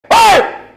Shouting Bouton sonore
Memes Soundboard0 views